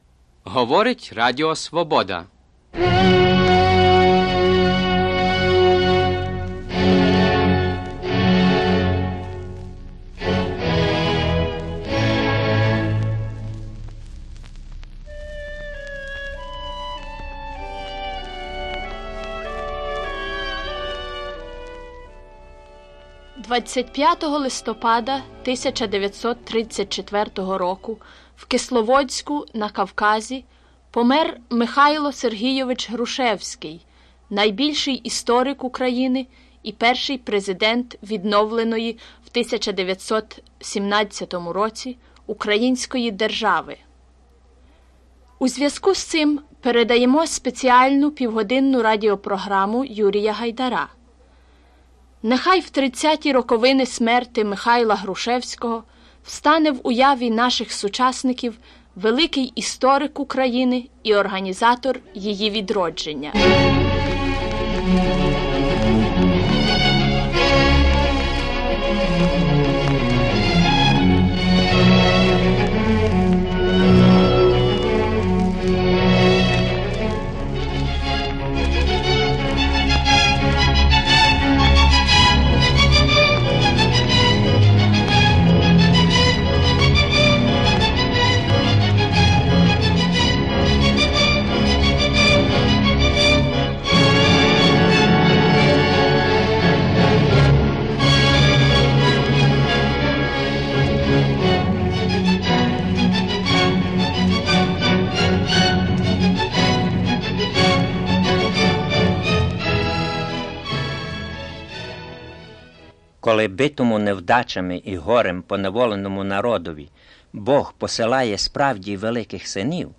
Radio Liberty program: Mykhailo Hrushevs’kyi